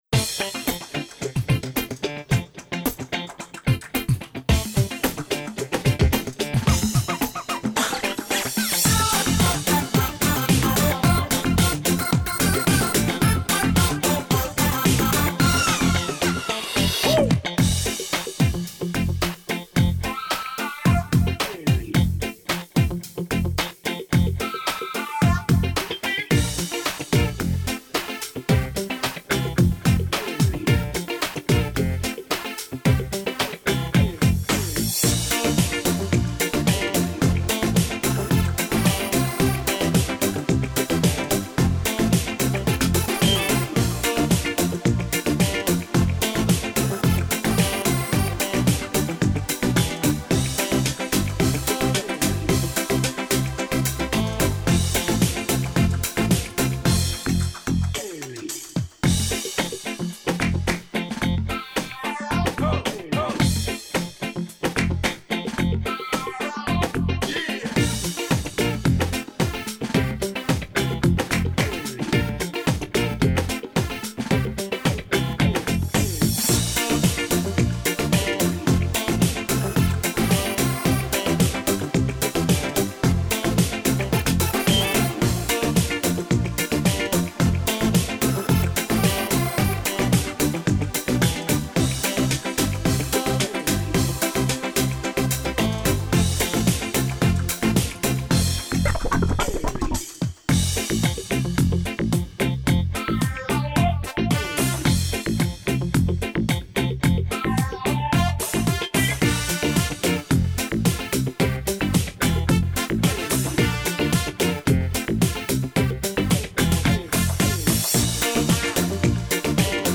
Пойте караоке